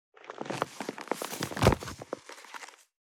441荷物を置く,トン,コト,ドサ,ストン,ガチャ,ポン,タン,スッ,ゴト,カチャ,
効果音室内物を置く